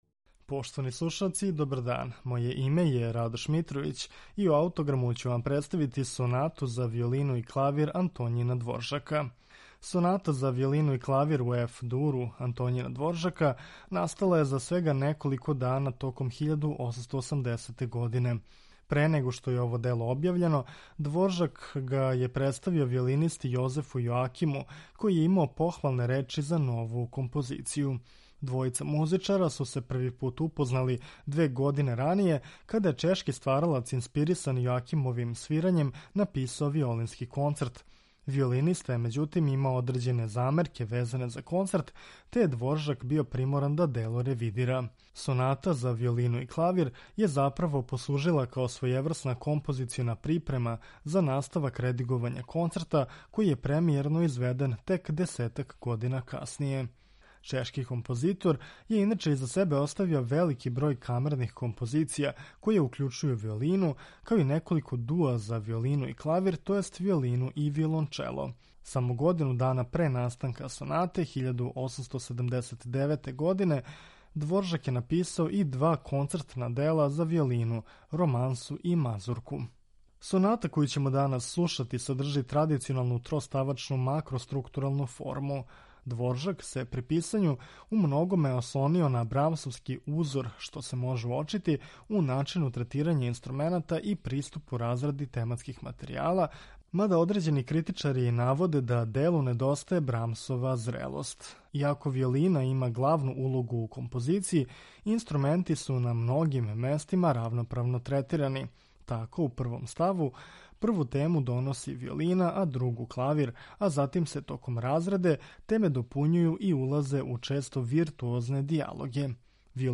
Чешки композитор се умногоме ослонио на брамсовски узор, користећи и квазифолклорне елементе у појединим сегментима дела. Ово Дворжаково остварење слушаћемо у извођењу виолинисте Јозефа Сука и пијанисте Јана Паненке.